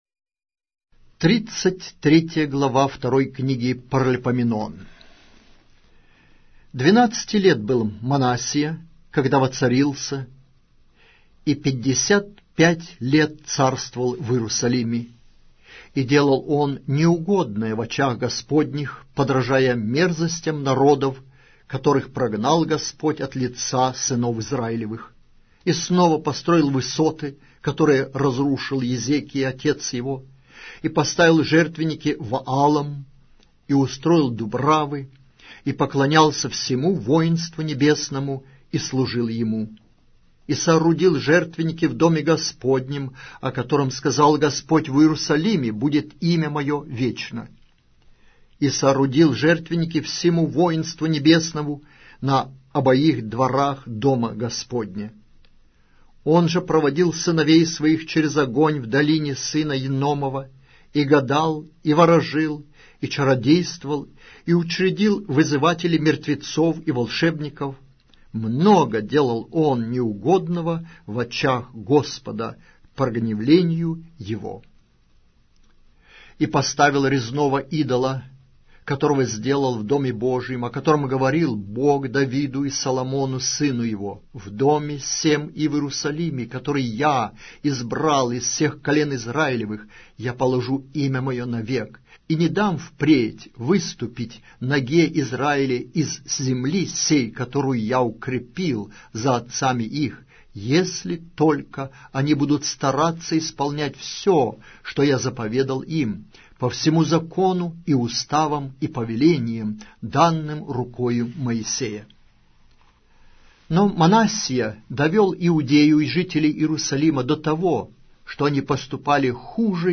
Аудиокнига: 2-я Книга. Паралипоменон